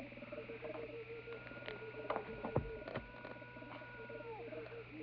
At 7:48 on the DVD, there is a strange background noise occurring. Possibly it's supposed to be some kind of bird call or animal howl but it sounds more like a person yelling.
strange howl (I later found this same sound in many later episodes during both second and third seasons.)
strange_howl.wav